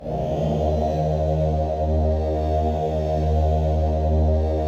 WAIL RIP 1.wav